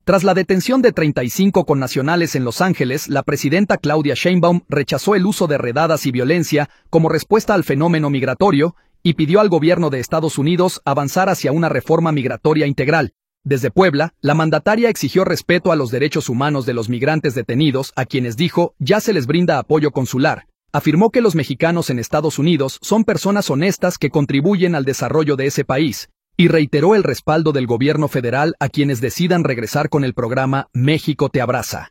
Desde Puebla, la mandataria exigió respeto a los derechos humanos de los migrantes detenidos, a quienes —dijo— ya se les brinda apoyo consular.